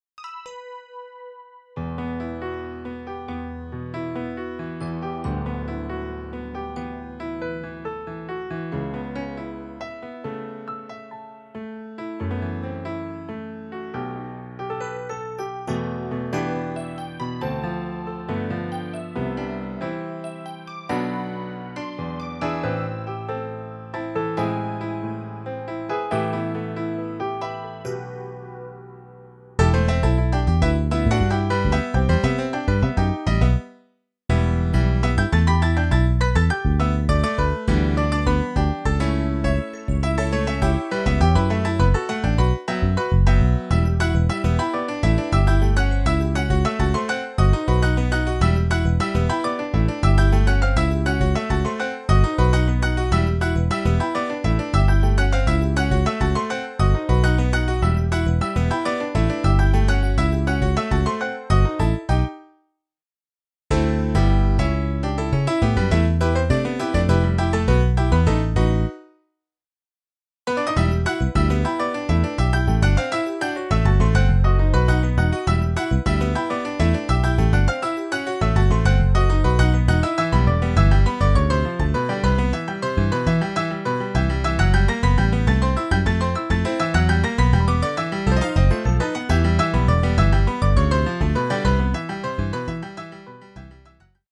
Saxo Tenor, 3X Trompetas, Piano, Teclado Sintetizador, Bajo